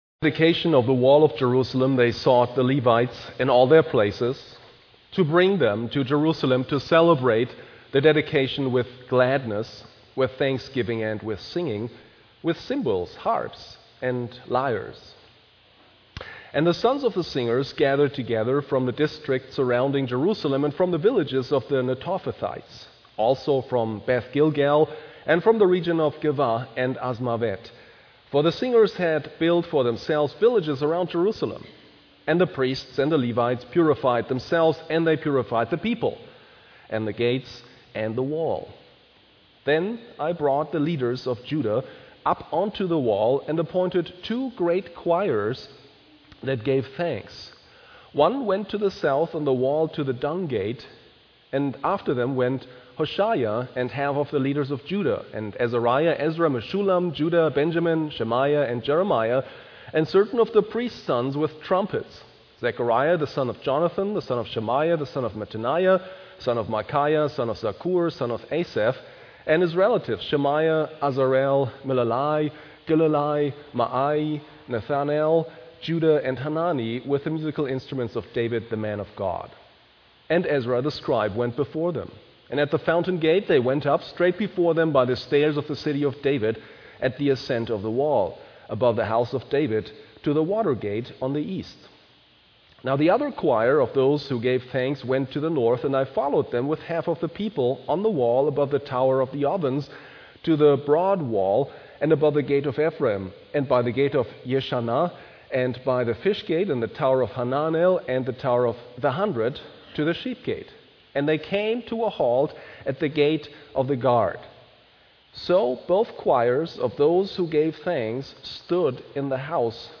Ezra/Neh — Browse Sermons | WPC